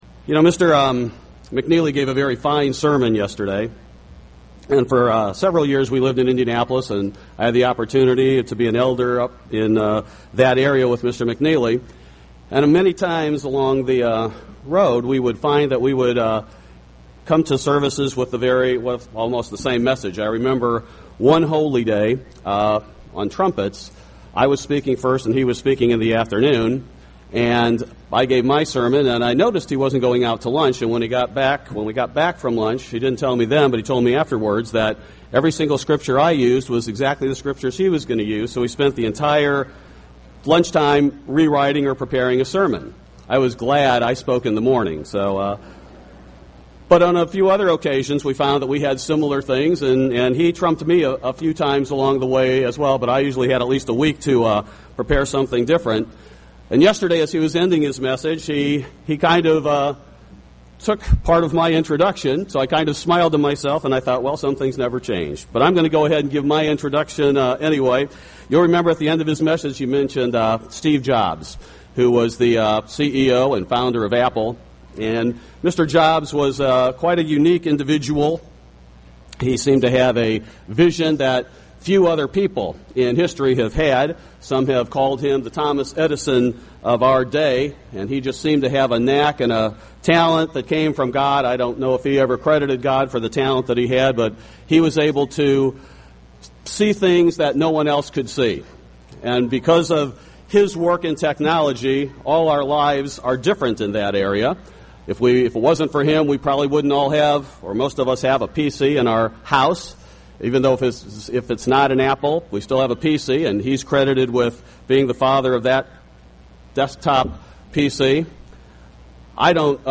This sermon was given at the Jekyll Island, Georgia 2011 Feast site.